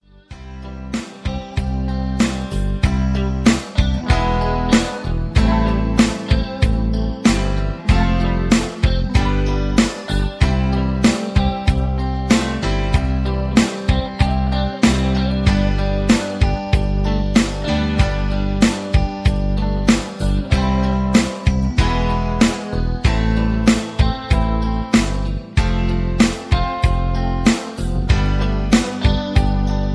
Backing Tracks for Professional Singers.